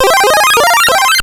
SE_MegaPowerup.wav